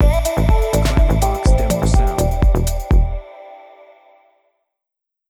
“Skyline Deep” Clamor Sound Effect
Can also be used as a car sound and works as a Tesla LockChime sound for the Boombox.